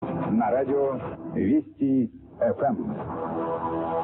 Vesti FM Russia via Moldova - Test Transmission - Station ID Recording
Vesti FM Russia Test via Moldova - 2000 UTC - 15175 Khz - May 20, 2025